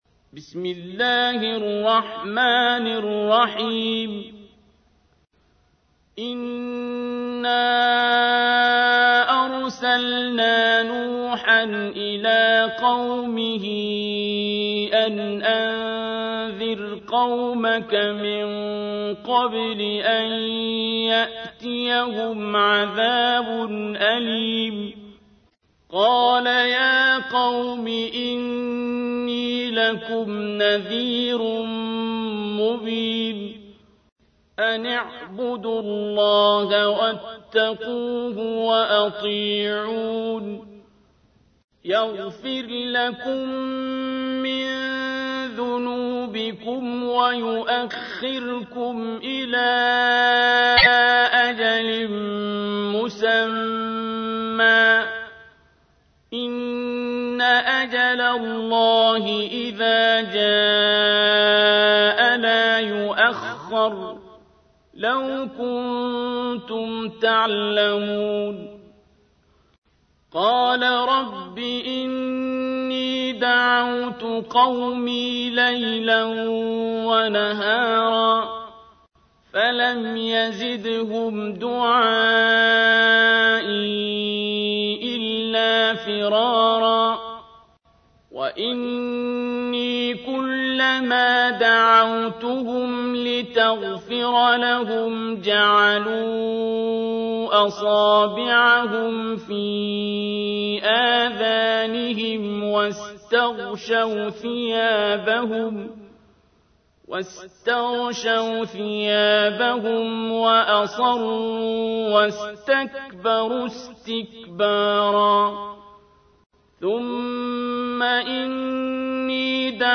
تحميل : 71. سورة نوح / القارئ عبد الباسط عبد الصمد / القرآن الكريم / موقع يا حسين